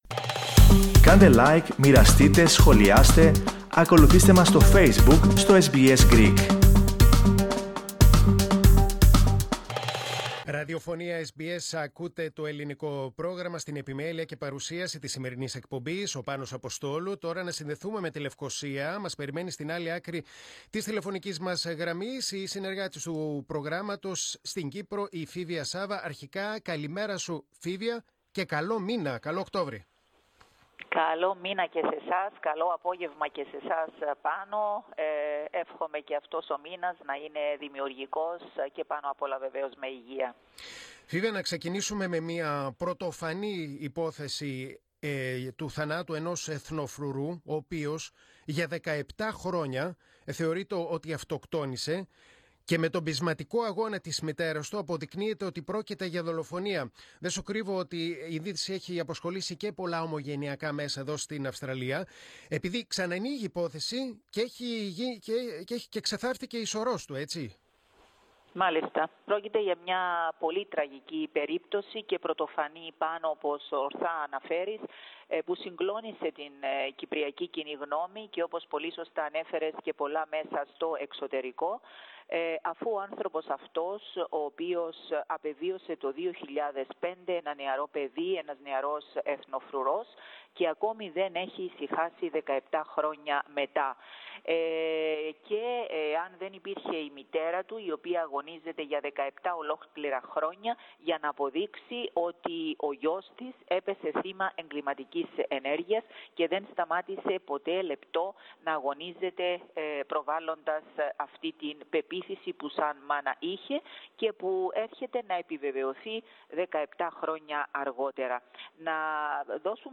Ακούστε, όμως, ολόκληρη την ανταπόκριση από την Κύπρο.